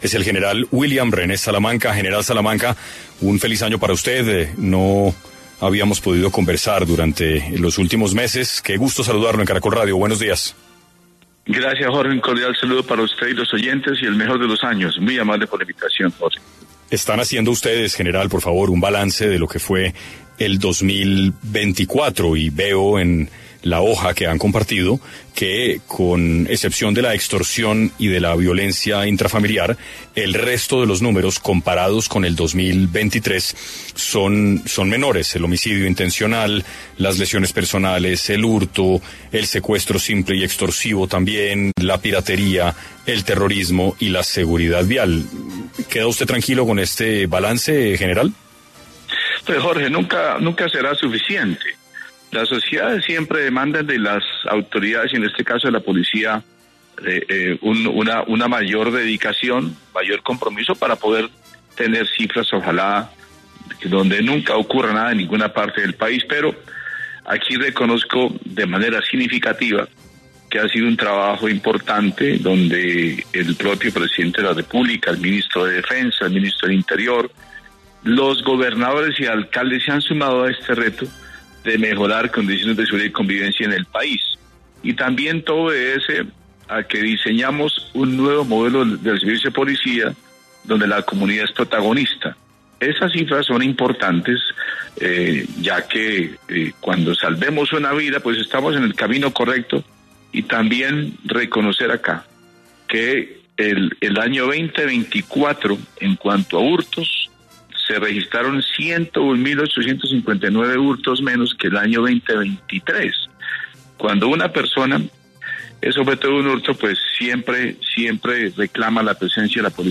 En 6AM de Caracol Radio estuvo Gral. William Salamanca, director Policía Nacional, quien habló sobre el balance de seguridad que se llevo a cabo en el 2024.